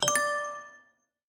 notifier_chime.opus